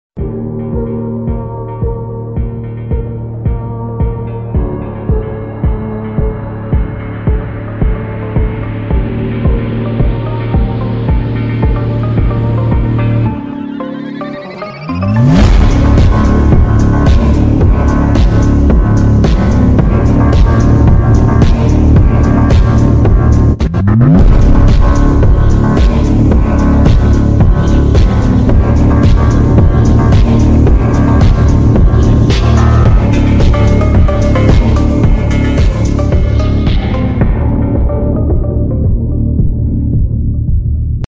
suara x rave